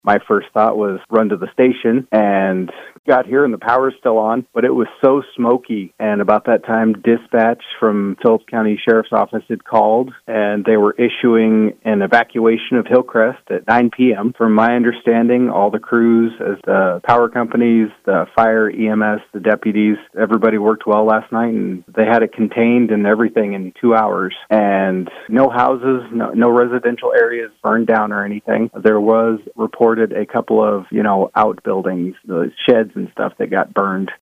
Wednesday evening, an uncontrolled wildfire south of Malta prompted an advisory for residents to temporarily evacuate to the town’s north side while firefighting crews worked to contain the blaze. KMMR 100.1 FM radio affiliate